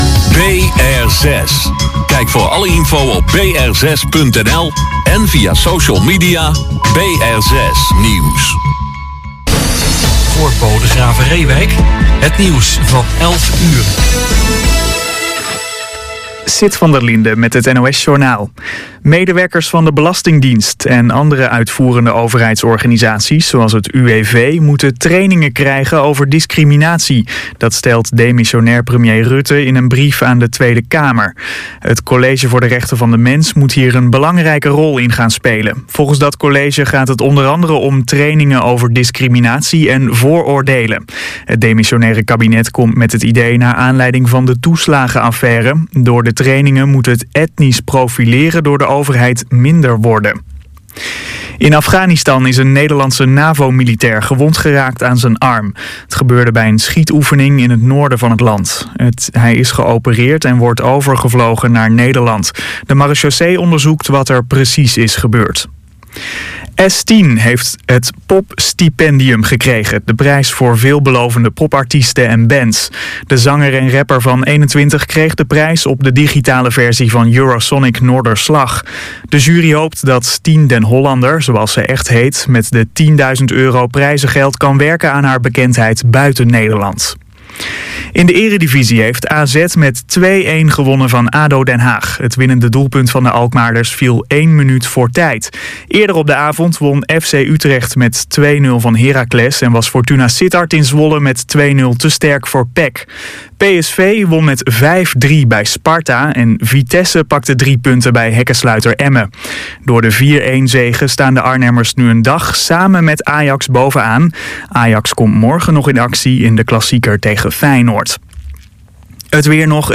“Riverside Jazz” wordt elke zaterdagavond uitgezonden via BR6, van 22:00 tot 00:00 uur.